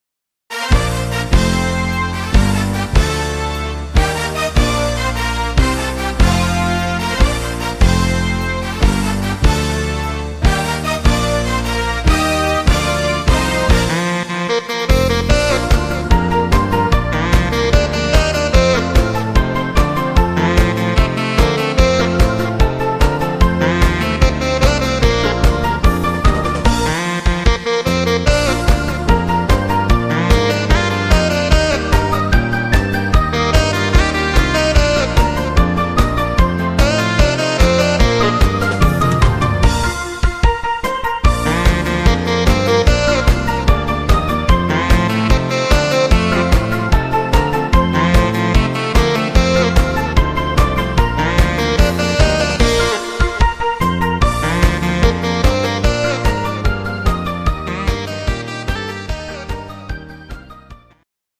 Instrumental Tenor Saxophon